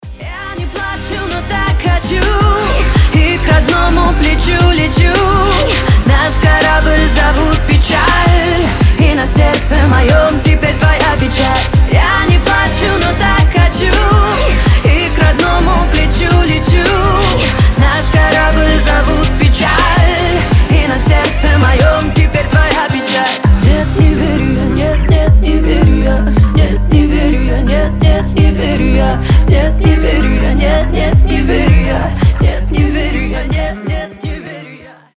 • Качество: 320, Stereo
громкие
грустные
женский голос